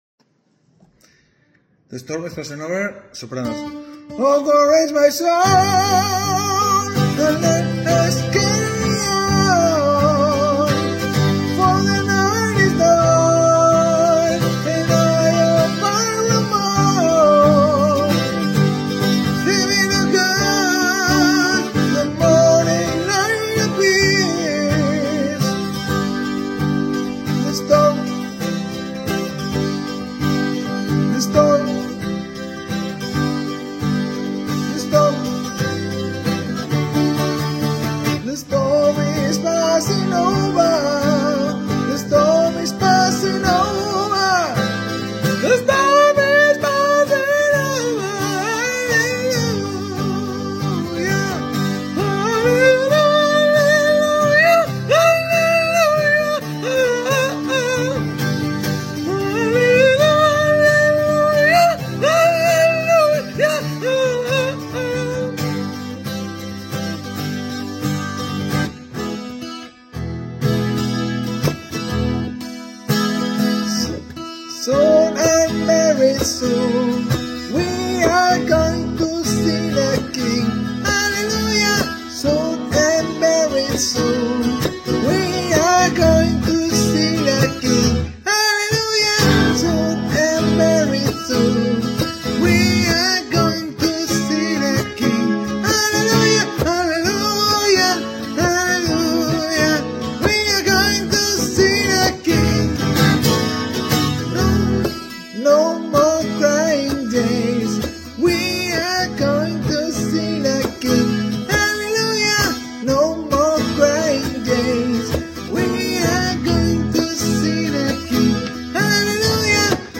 Sopranos
the-storm-is-passing-over-sopranos.mp3